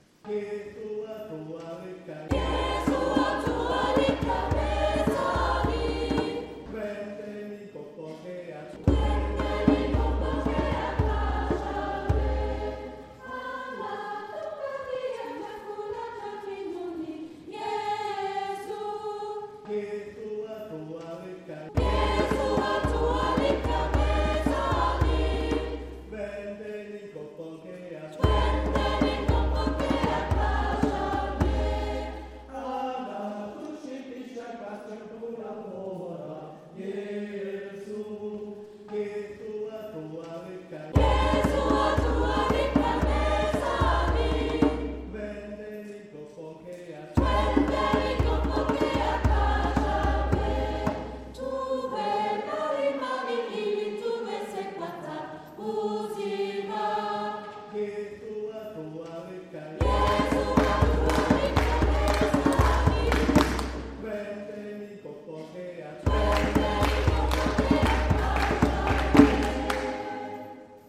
Die afrikanischen Lieder aus dem Gottesdienst
Unser Chor begleitete diese Messe mit neuen afrikanischen Gesängen die den einen oder anderen Kirchenbesucher zum "mitshaken" animierten.